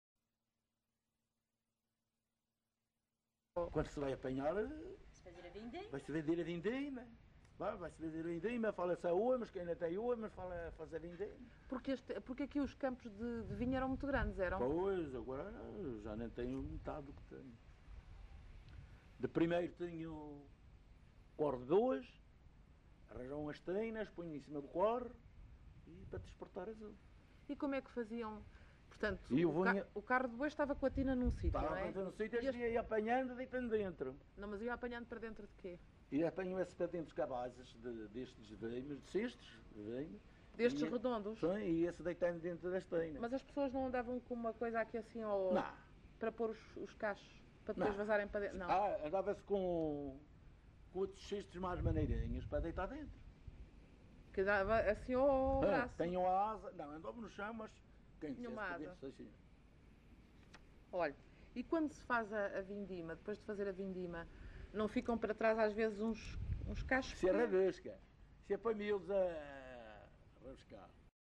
LocalidadeCamacha (Porto Santo, Funchal)